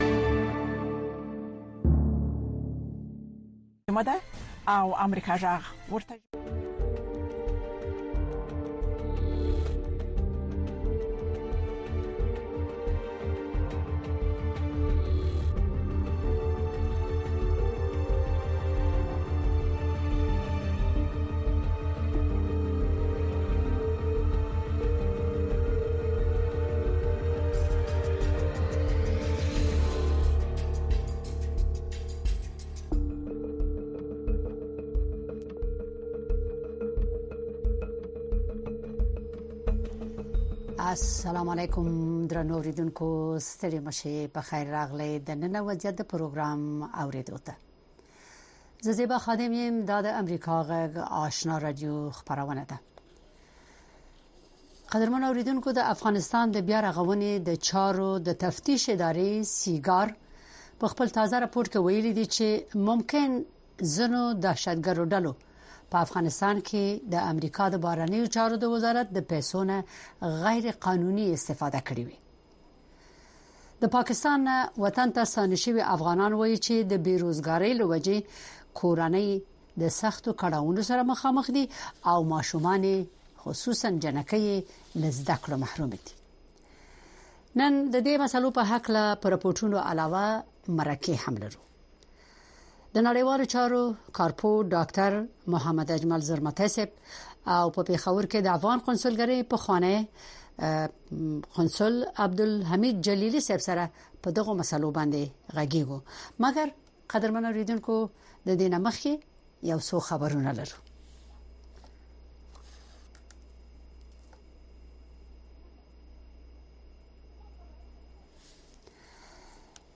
د نن او وضعیت په خپرونه کې د افغانستان د ټولنیز او فرهنګي وضعیت ارزونه ددې خپرونې له میلمنو څخه اورئ. دغه خپرونه هره شپه د ٩:۳۰ تر ۱۰:۰۰ پورې په ژوندۍ بڼه ستاسې غږ د اشنا رادیو د څپو او د امریکا غږ د سپوږمکۍ او ډیجیټلي خپرونو له لارې خپروي.